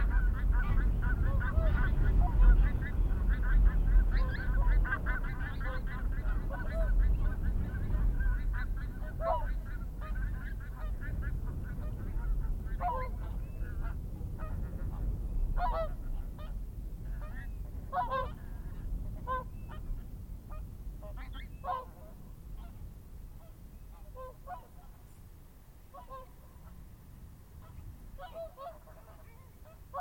Oie à bec court - Mes zoazos
oie-bec-court.mp3